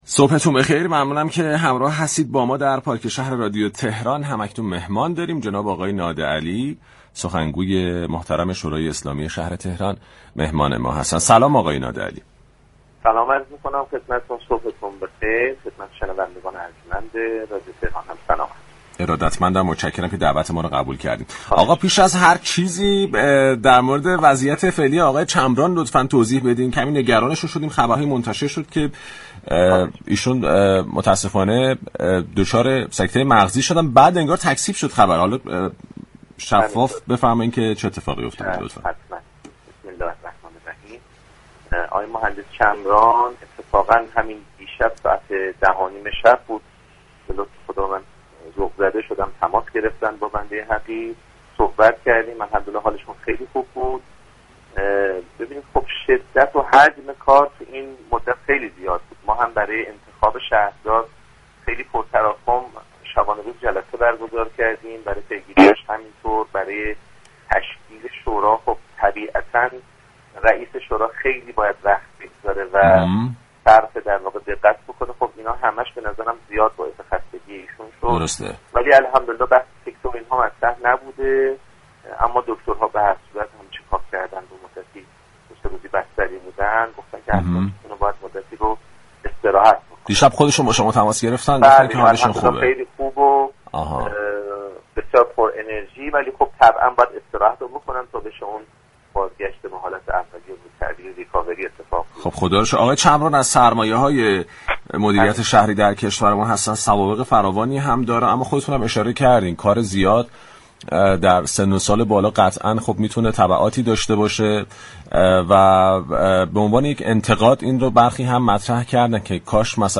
به گزارش پایگاه اطلاع رسانی رادیو تهران، علیرضا نادعلی سخنگوی شورای شهر تهران درخصوص اخبار منتشر شده مبنی بر سكته مغزی مصطفی چمران رییس شورای شهرتهران در گفتگو با پارك شهررادیو تهران گفت: بحث سكته مغزی مطرح نبوده و من شب گذشته(دوشنبه 8 شهریور) با آقای چمران صحبت كردم.